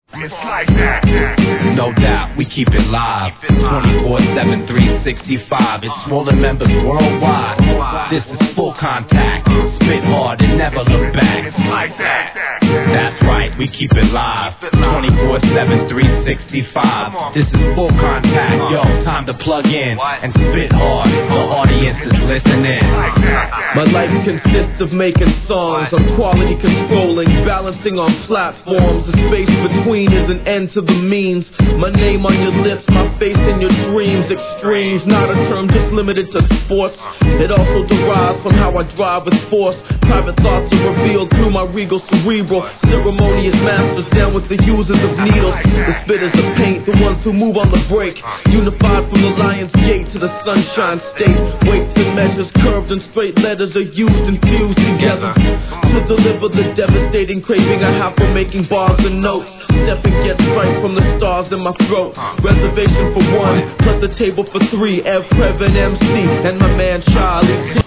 HIP HOP/R&B
シンプルなBEATをMICリレー！！